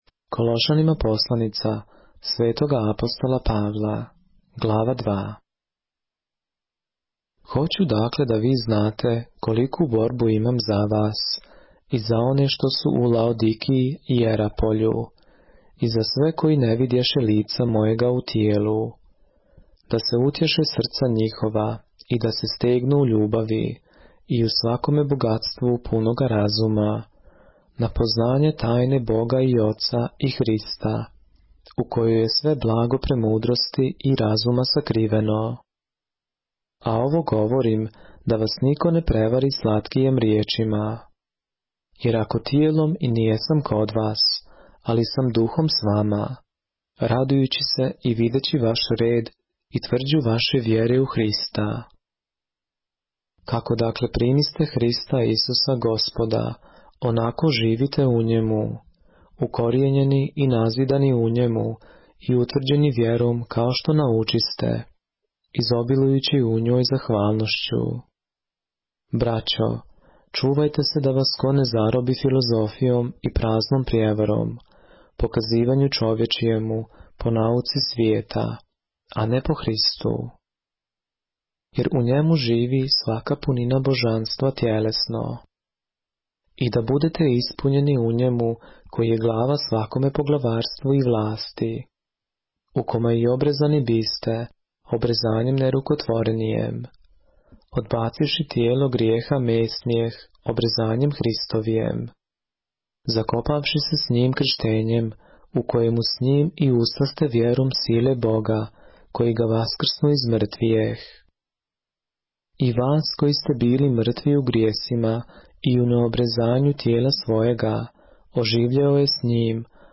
поглавље српске Библије - са аудио нарације - Colossians, chapter 2 of the Holy Bible in the Serbian language